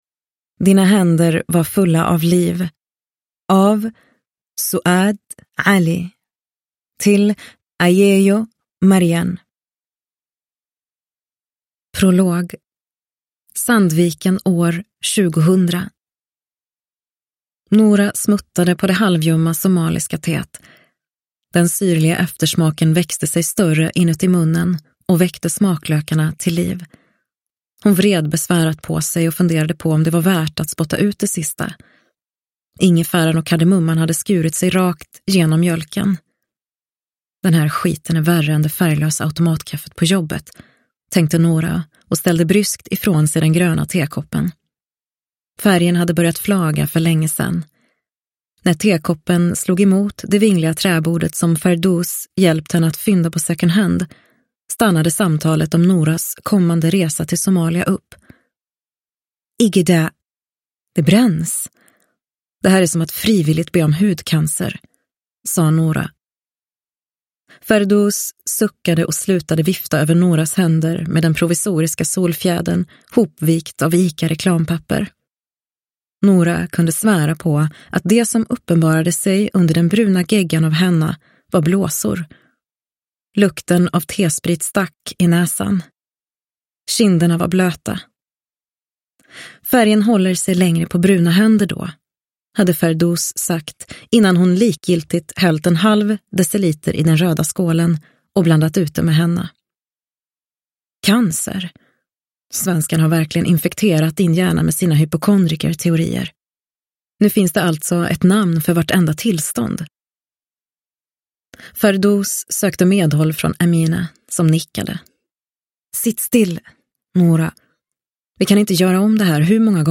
Dina händer var fulla av liv – Ljudbok – Laddas ner